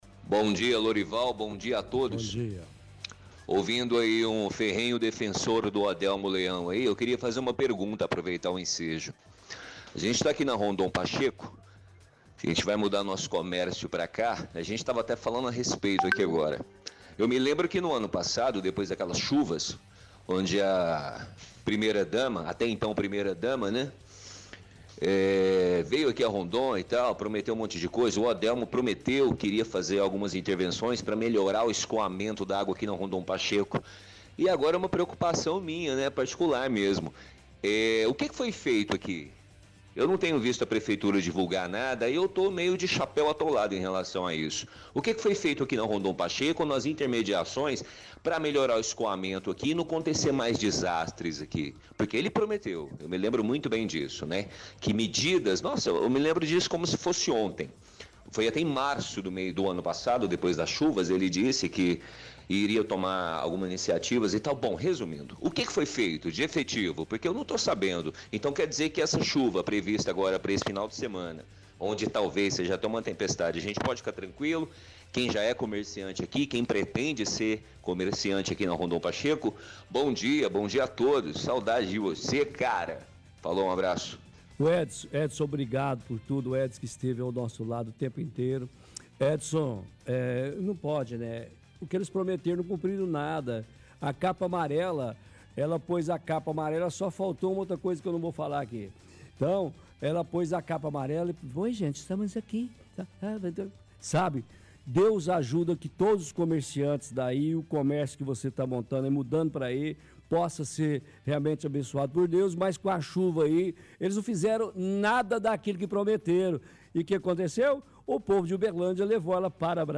– Ouvinte diz que lembra de promessas do prefeito para evitar enchentes na Rondon e questiona o que foi feito de efetivo.